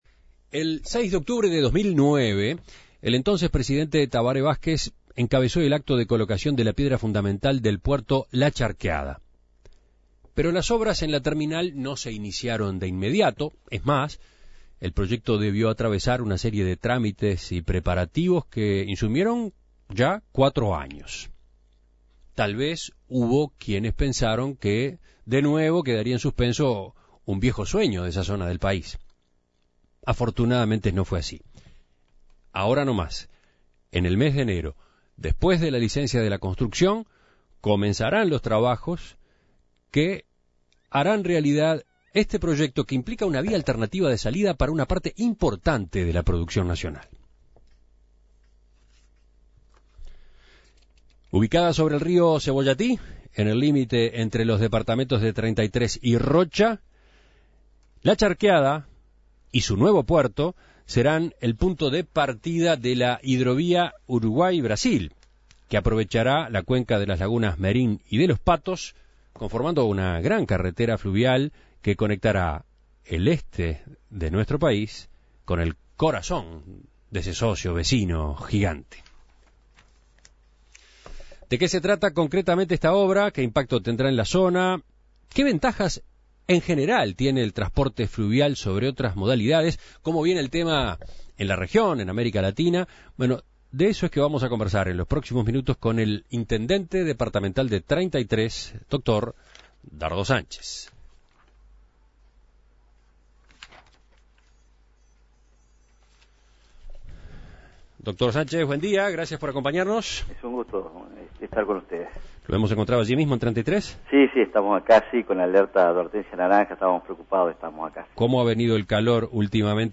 En Perspectiva dialogó con el intendente de Treinta y Tres, Dardo Sánchez, quien cree que se debe apostar al desarrollo de las hidrovías en todo el país porque serían un gran solución al tema de la infraestructura de carretera deficitaria.